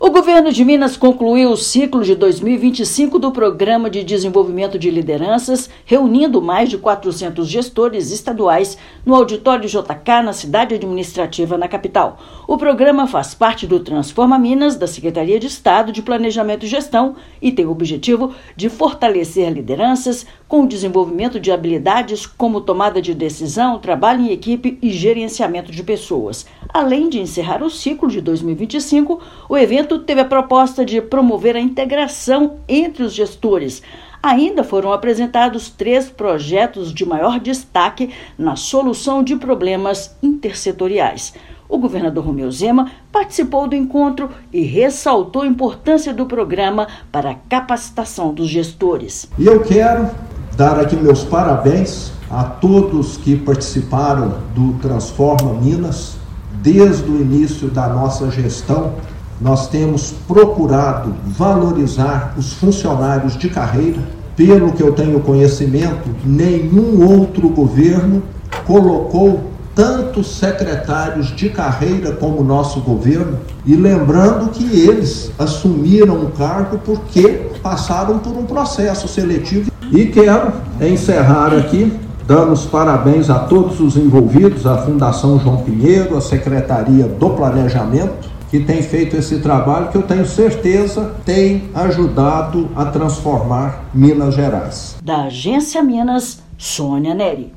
PDL faz parte do Transforma Minas e visa aprimorar as competências, habilidades e comportamentos de gestão e liderança no Estado. Ouça matéria de rádio.